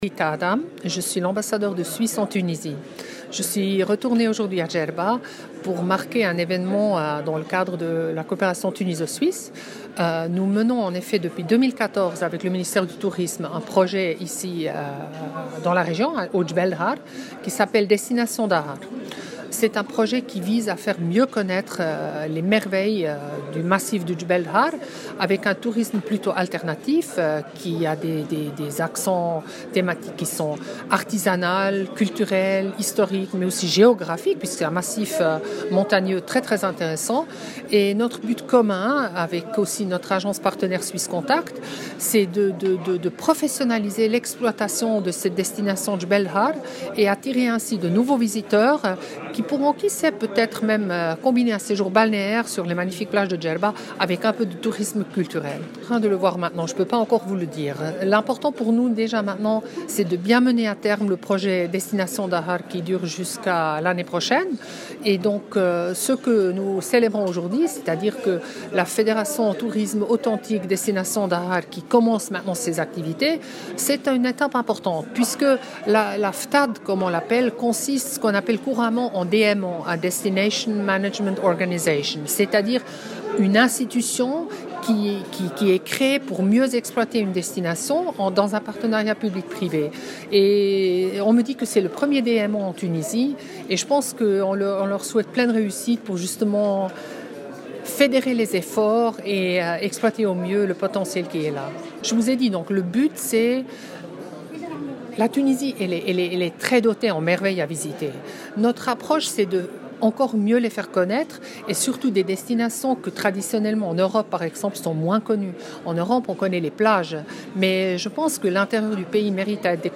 قالت سفيرة سويسرا في تونس، ريتا آدام، اليوم الثلاثاء إن دولتها تهتم بالترويج لوجهات سياحية مهمة في تونس مشيرة إلى وجهة جبال الظاهر بالجنوب الشرقي التونسي والتي تعتبر من الوجهات السياحية المميزة، بحسب تعبير السفيرة في تصريح لـ "الجوهرة أف أم".